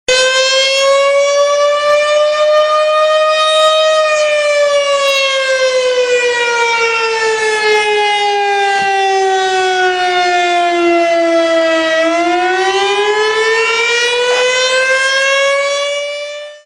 firesiren.mp3